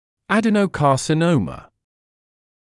[ˌædənəˌkɑːsɪ’nəumə][ˌэдэнэˌкаːси’ноумэ]аденокарцинома